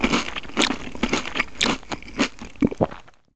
footsteps/n97.wav · s3prl/Nonspeech at main
Nonspeech